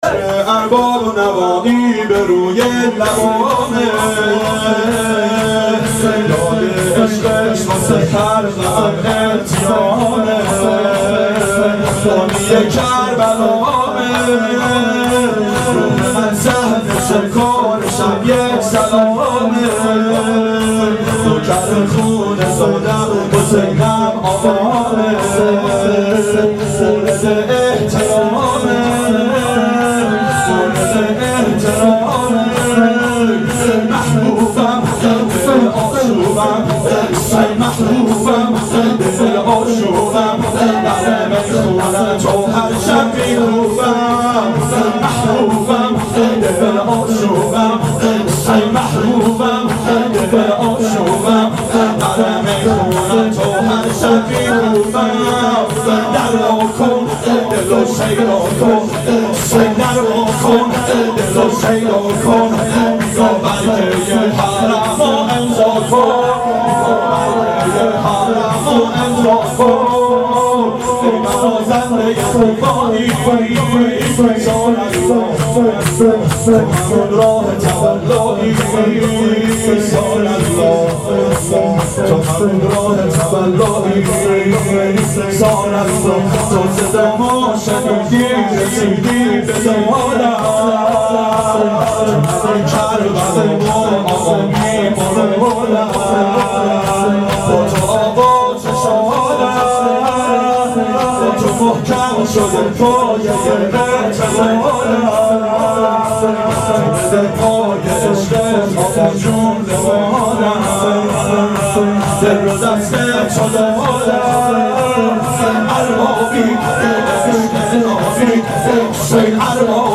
• شب اربعین 92 هیأت عاشقان اباالفضل علیه السلام منارجنبان